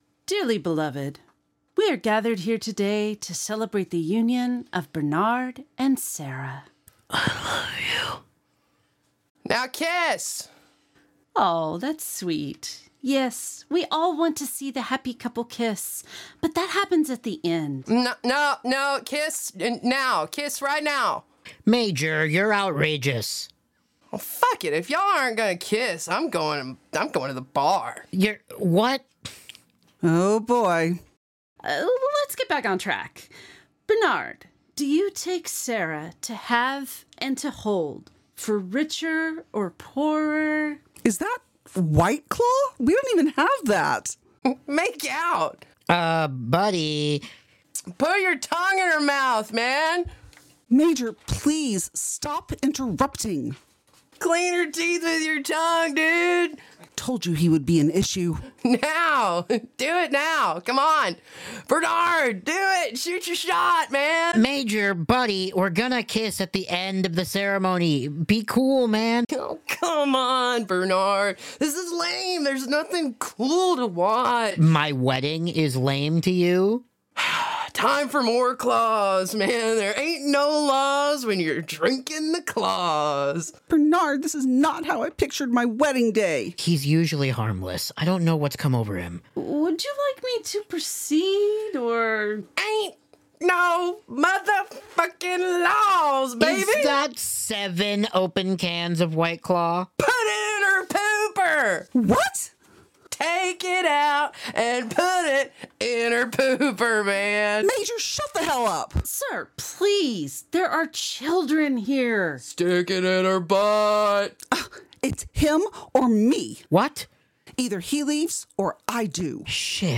Format: Audio Drama
Voices: Solo
Genres: Comedy